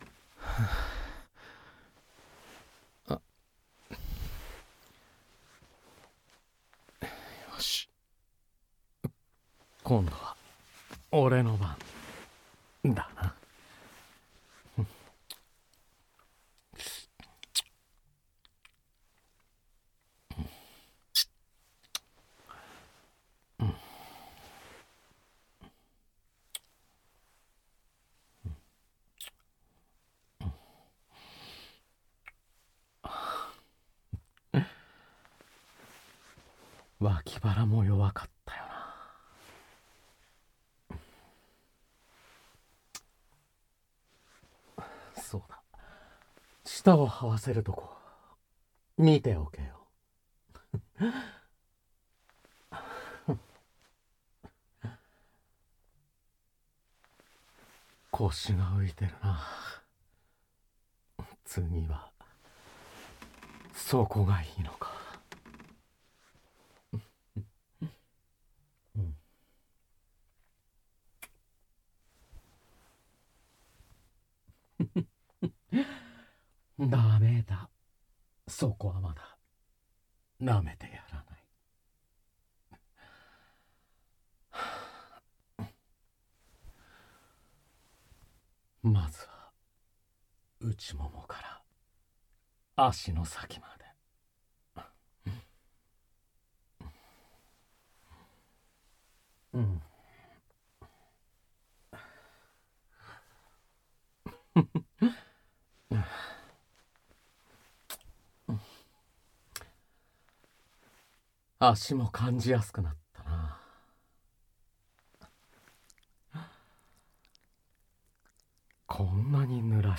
●18歳以上推奨　●全編ダミーヘッドマイクにて収録